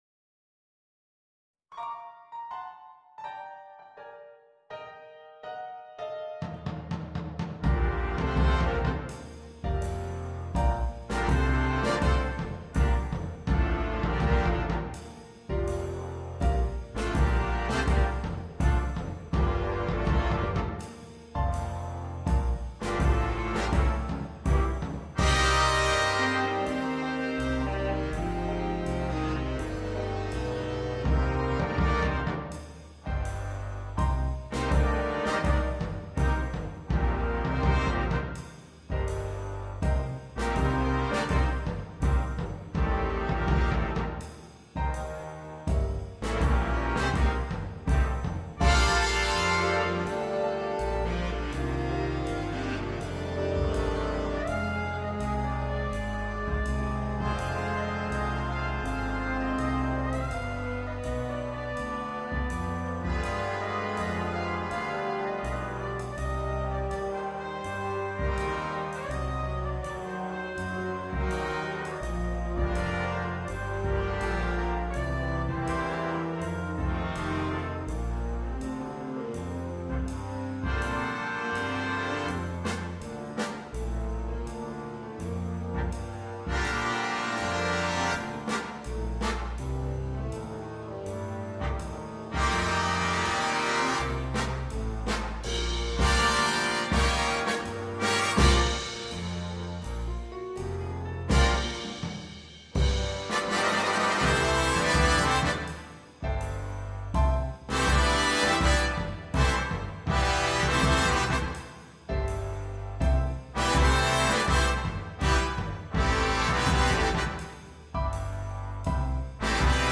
для биг-бэнда.